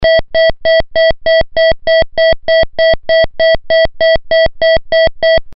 Sirena electrónica